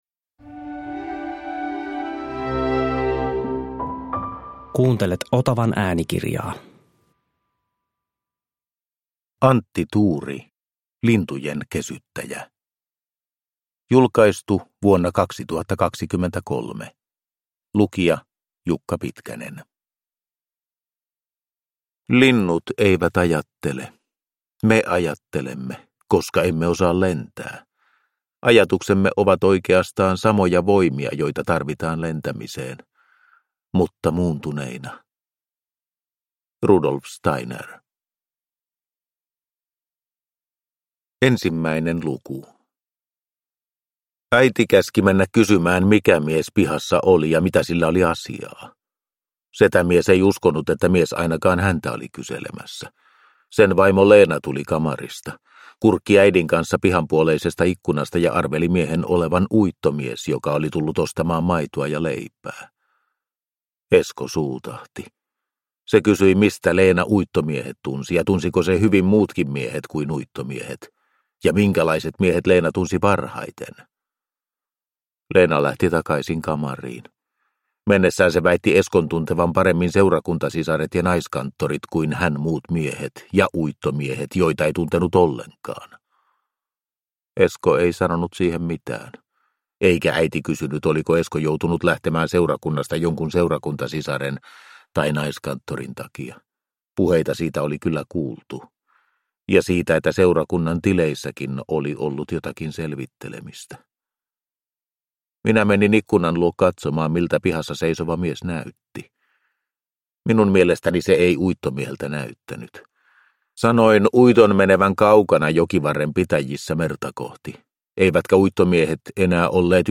Lintujen kesyttäjä – Ljudbok – Laddas ner